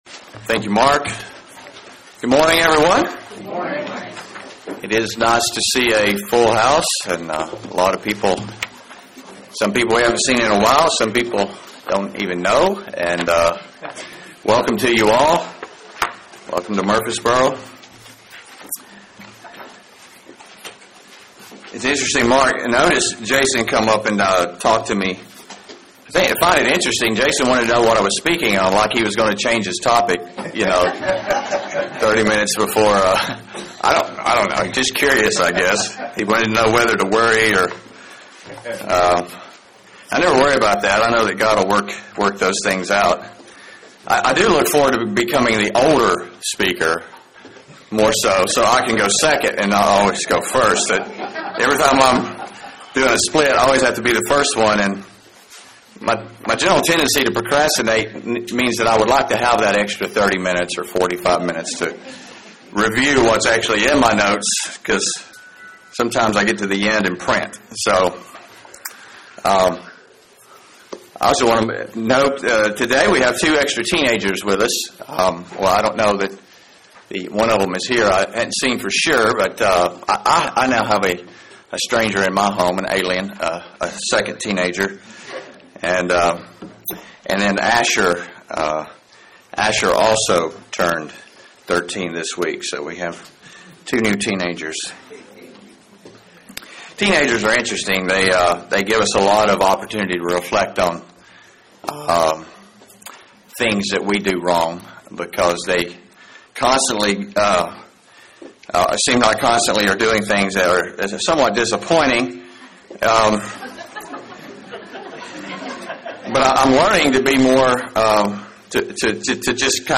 Given in Murfreesboro, TN
UCG Sermon Studying the bible?